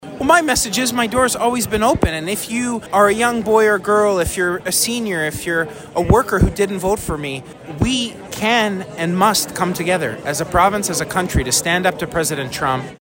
Piccini had this message for those who didn’t vote for him last night.